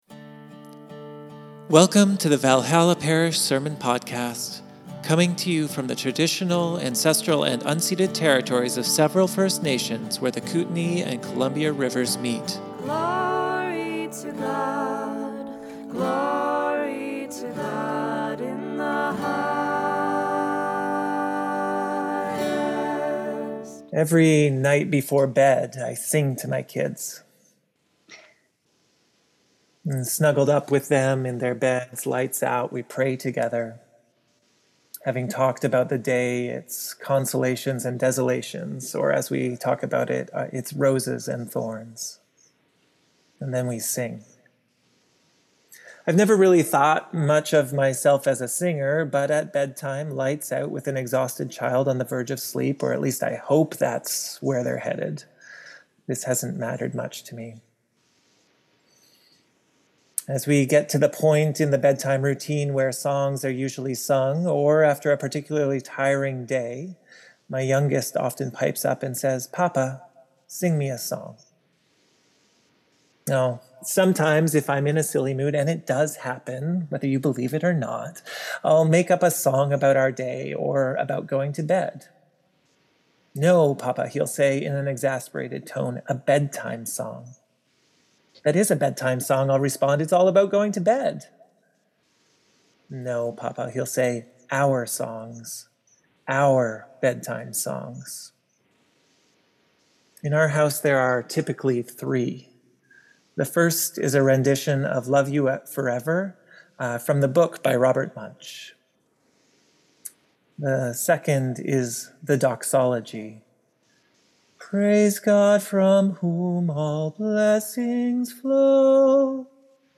Sermons | Valhalla Parish